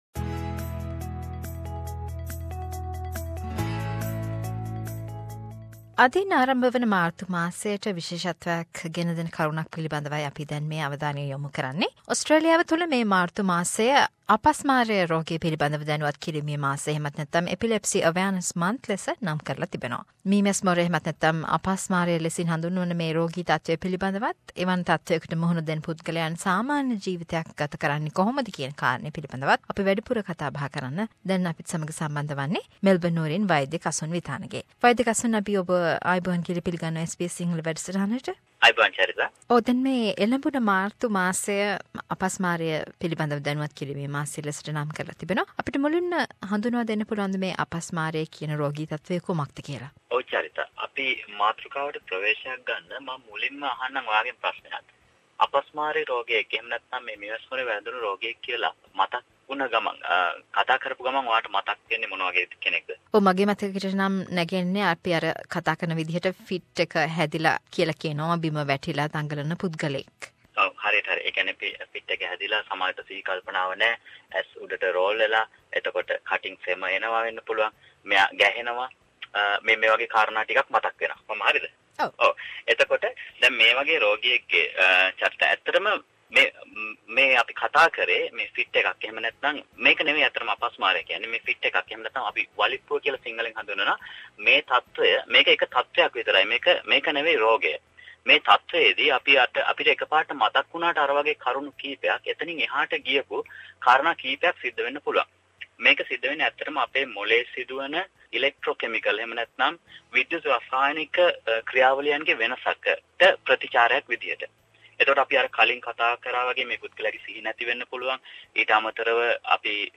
March is allocated to educate people about Epilepsy. Here is a medical discussion